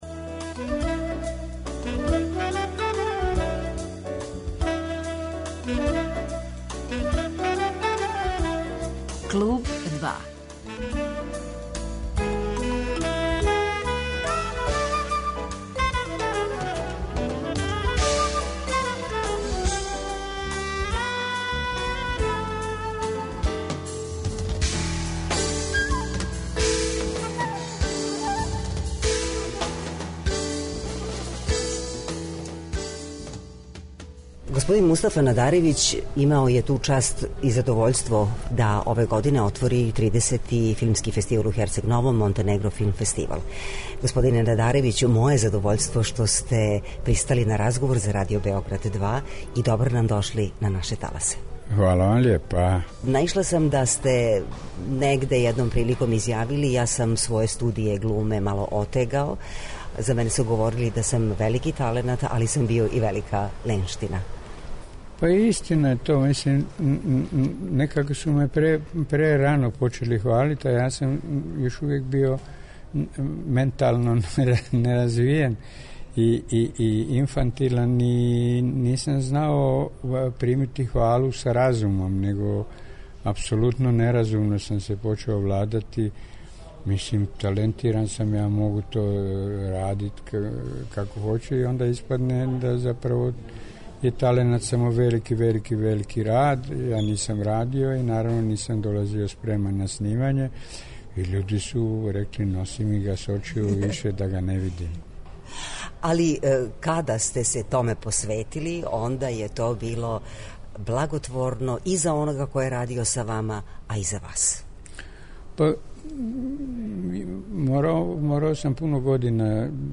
Гост 'Клуба 2' је Мустафа Надаревић, глумац добитник награде 'Милан Жмукић' за животно дело и допринос филмској уметности.